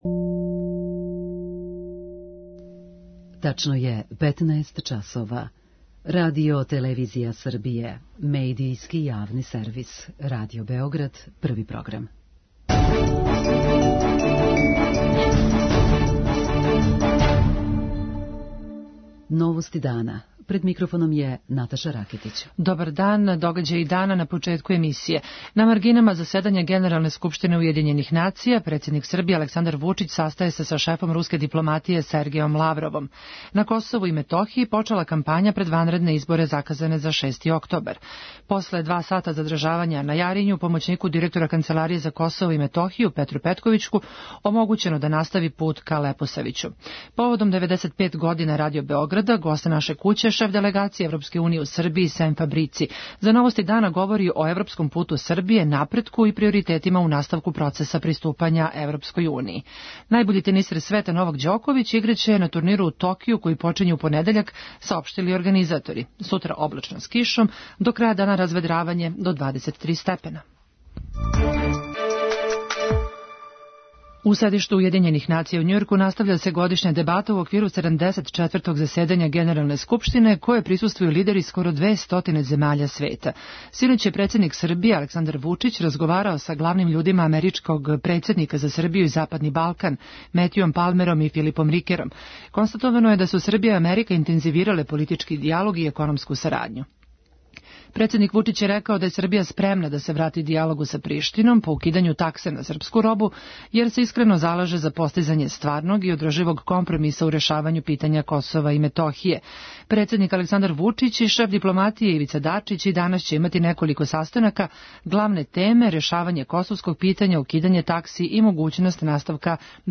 Поводом 95 година Радио Београда, гост наше куће шеф Делегације ЕУ у Србији Сем Фабрици. За Новости дана говори о европском путу Србије, позитивном напретку и приоритетима у наставку процеса приступања ЕУ.